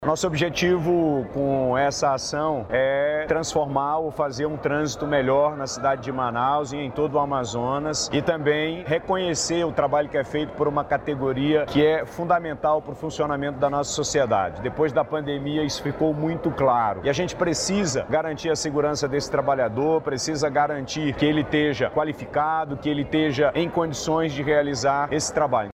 Durante o lançamento da Plataforma, o Governador do Amazonas, Wilson Lima, também entregou 250 kits de segurança e identificação para motofretistas de Manaus, e destacou a importância da categoria para a economia local.